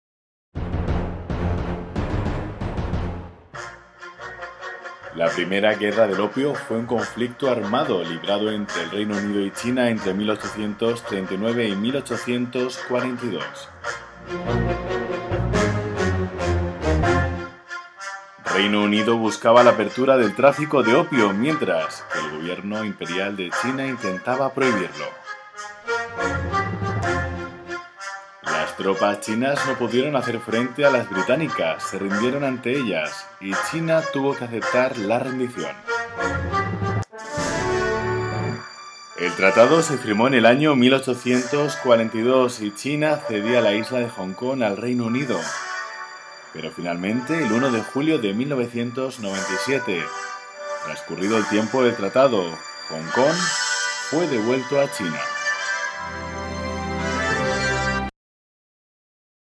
Spanish professional announcer
kastilisch
Sprechprobe: eLearning (Muttersprache):